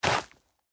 Minecraft Version Minecraft Version 1.21.5 Latest Release | Latest Snapshot 1.21.5 / assets / minecraft / sounds / block / soul_soil / step5.ogg Compare With Compare With Latest Release | Latest Snapshot